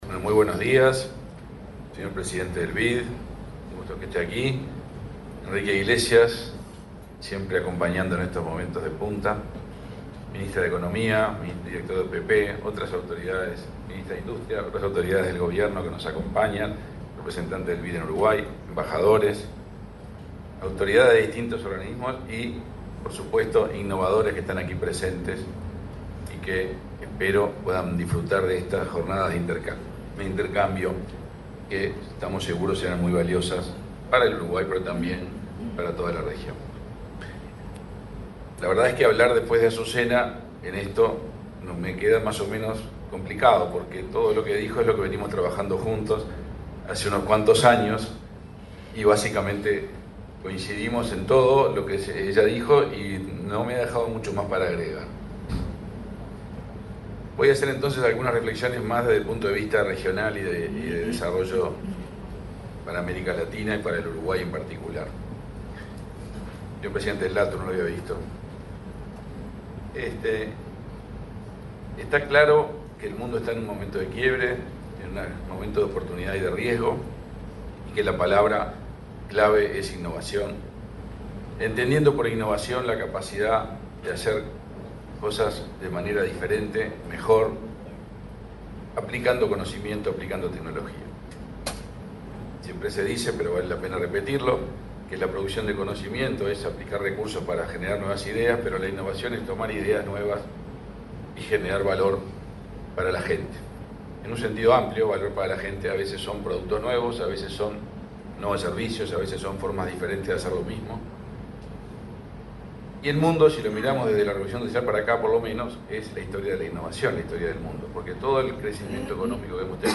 Palabras del canciller, Omar Paganini
Palabras del canciller, Omar Paganini 29/08/2024 Compartir Facebook X Copiar enlace WhatsApp LinkedIn Este jueves 29, el canciller de la República, Omar Paganini, disertó en el Foro de Innovación e Inteligencia Artificial de Uruguay: El Camino hacia un Hub de Innovación Global. El evento se realizó en el Laboratorio Tecnológico del Uruguay.